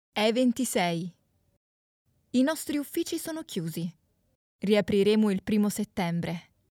Female
Phone Greetings / On Hold
Words that describe my voice are Engage, Natural, Energic.